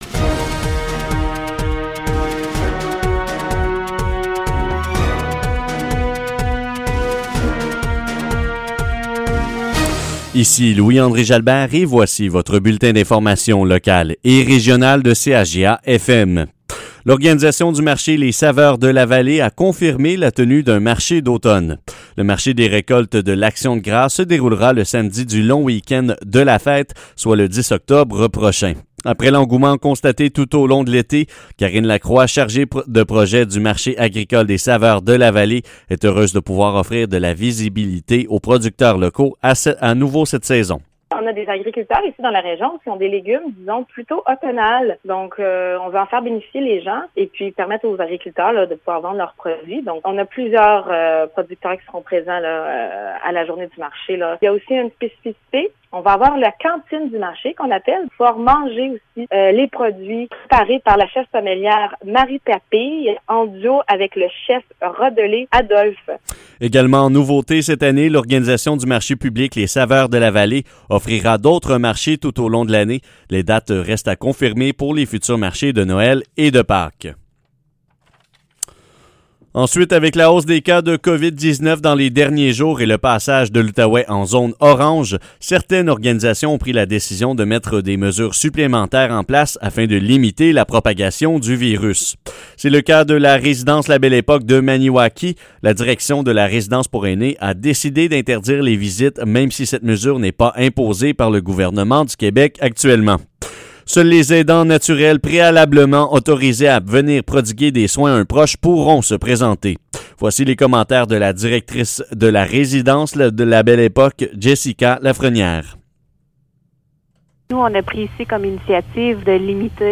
Nouvelles locales - 29 septembre 2020 - 12 h